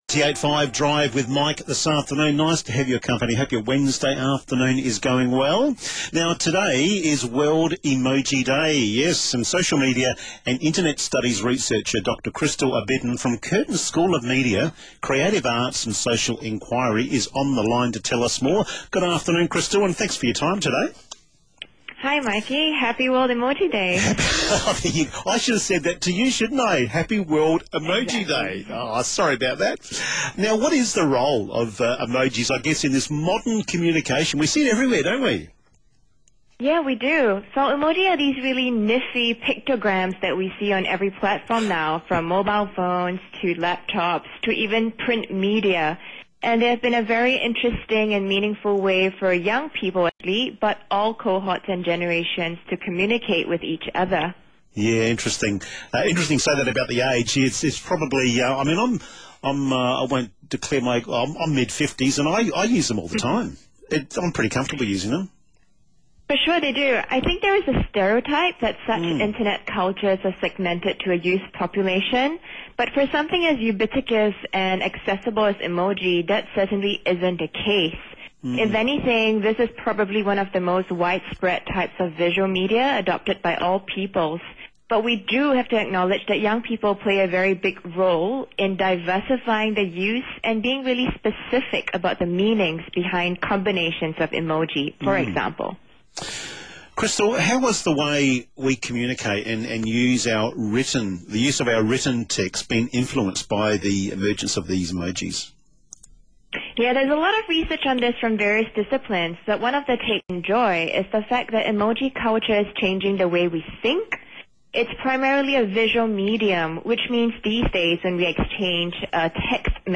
Live radio: World Emoji Day & Hidden Instagram Likes